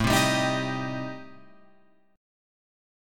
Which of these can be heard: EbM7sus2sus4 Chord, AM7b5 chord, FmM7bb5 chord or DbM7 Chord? AM7b5 chord